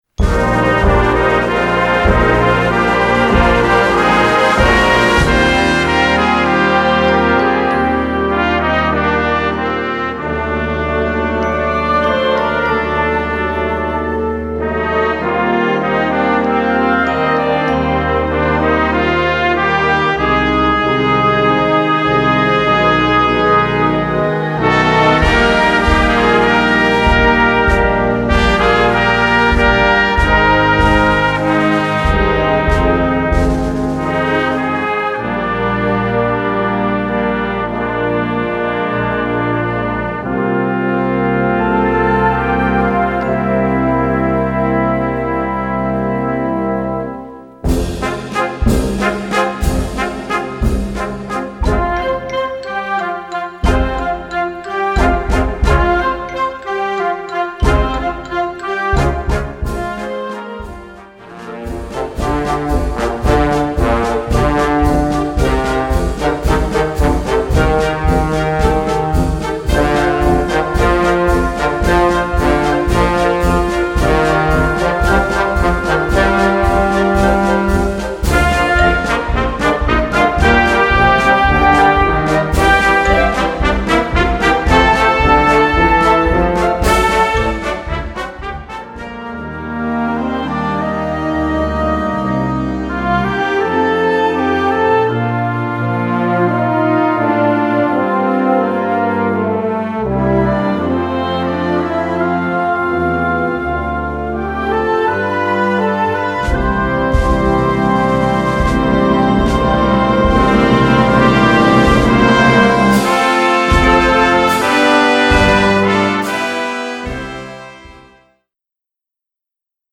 Gattung: Filmmusik Jugend
Besetzung: Blasorchester